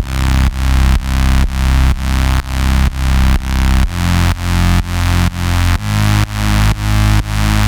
Бас сэмпл (Минимал-Электро): Ultra Phat
Тут вы можете прослушать онлайн и скачать бесплатно аудио запись из категории «Minimal Electro».